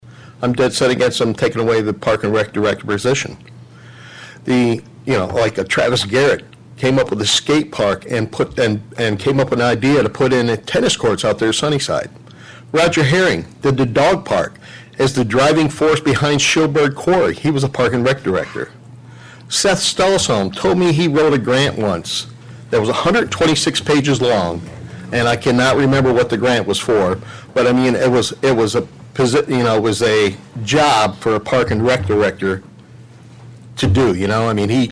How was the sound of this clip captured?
During the Public Forum portion of the meeting